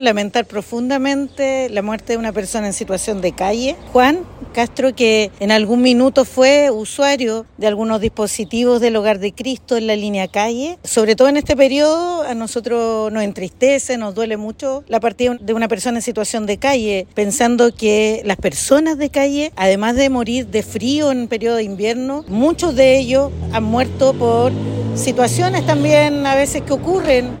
En conversación con La Radio